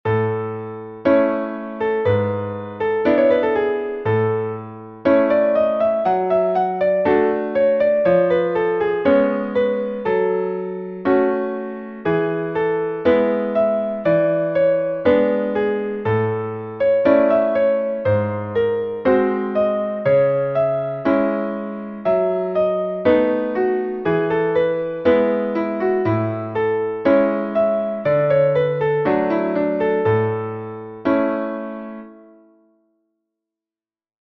Pero nesta ocasión vai ser unha análise só para piano, con dous pentagramas, para ir traballando outro tipo de formatos.